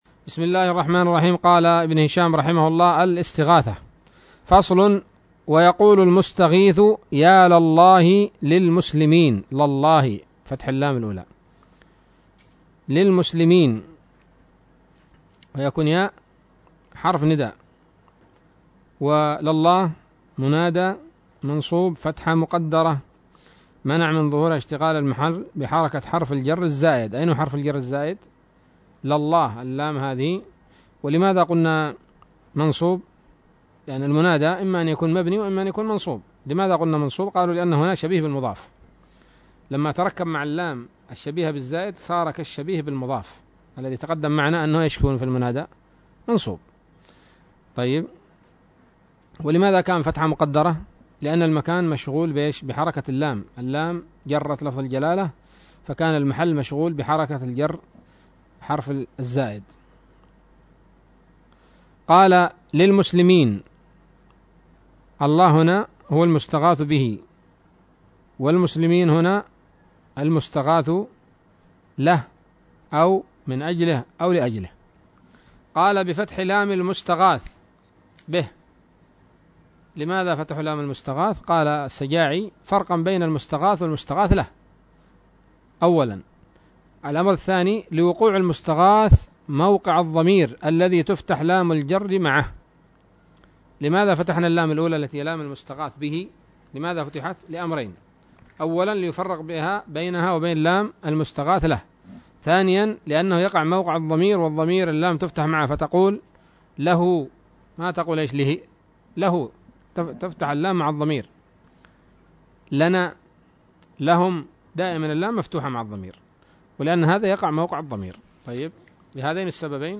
الدرس الحادي والتسعون من شرح قطر الندى وبل الصدى